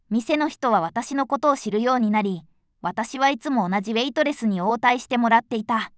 voicevox-voice-corpus / ita-corpus /No.7_ノーマル /EMOTION100_043.wav